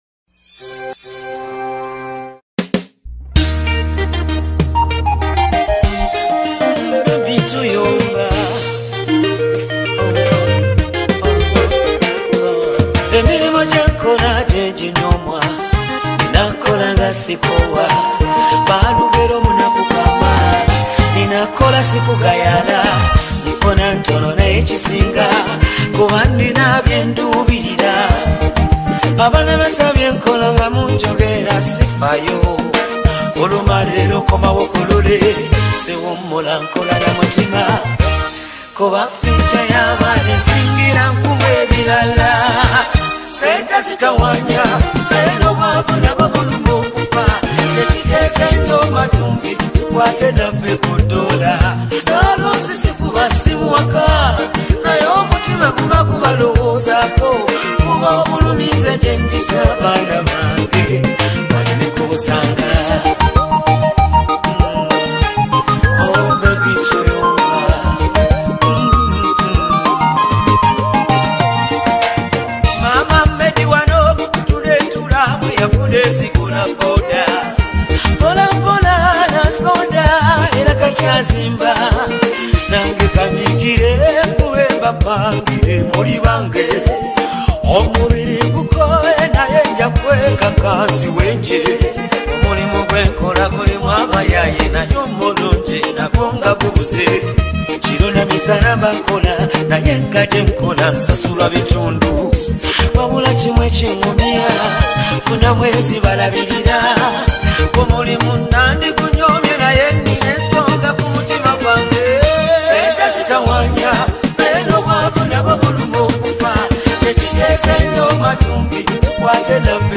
With his emotional delivery and clear message